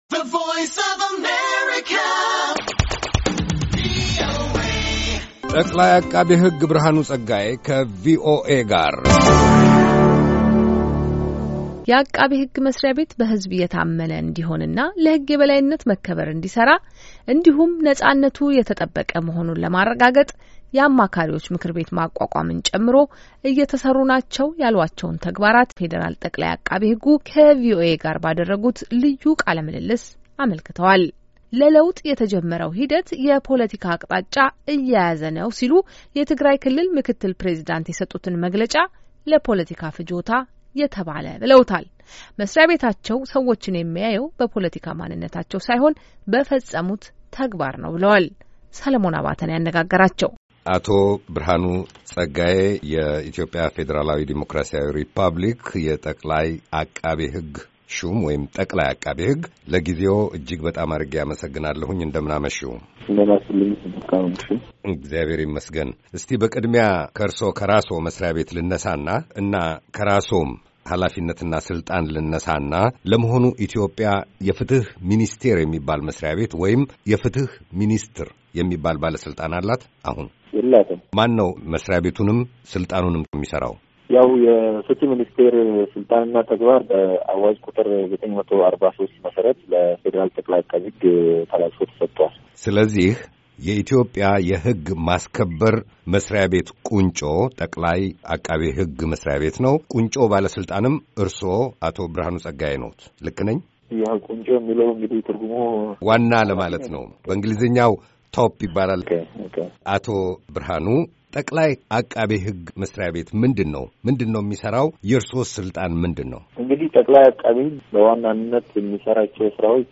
የአቃቤ ሕግ መሥሪያ ቤት በሕዝብ የታመነ እንዲሆንና ለሕግ የበላይነት መከበር እንዲሠራ እንዲሁም ነፃነቱ የተጠበቀ መሆኑን ለማረጋገጥ የአማካሪዎች ምክር ቤት ማቋቋምን ጨምሮ እየተሠሩ ናቸው ያሏቸውን ተግባራት ፌደራል ጠቅላይ አቃቤ ሕጉ ከቪኦኤ ጋር ባደረጉት ልዩ ቃለ-ምልልስ አመልክተዋል።